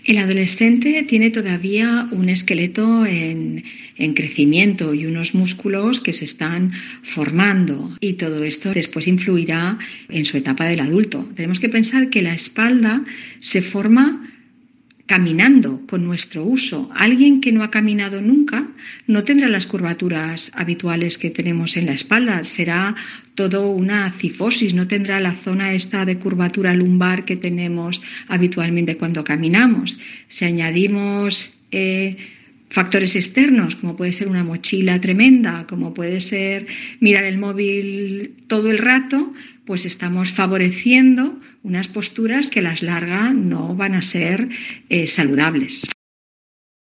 Traumatólogos explican en COPE como evitar los problemas de espalda en los adolescentes y corregir futuros problemas de adultos